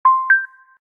FullBattery.ogg